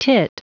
Prononciation du mot teat en anglais (fichier audio)
Prononciation du mot : teat